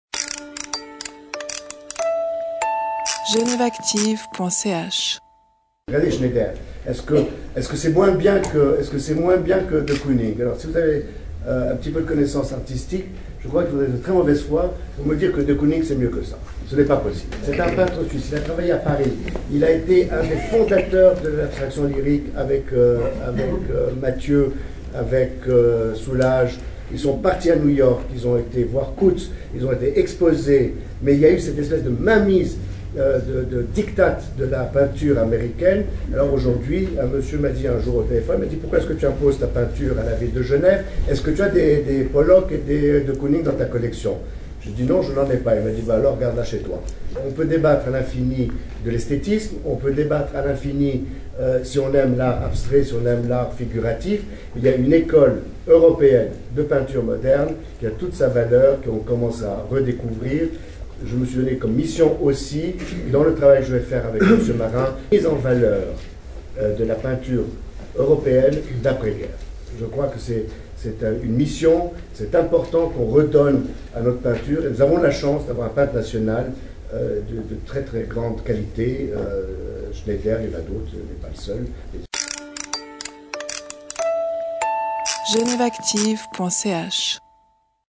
Jean-Claude Gandur parle de sa volonté de promouvoir l’Abstraction lyrique et notamment de la peinture de Gérard Schneider.